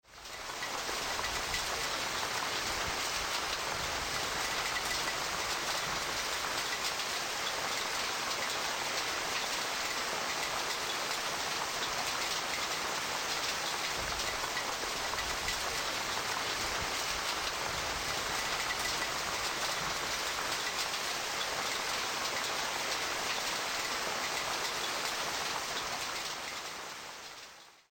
Звуки града
Градины сыплются во дворе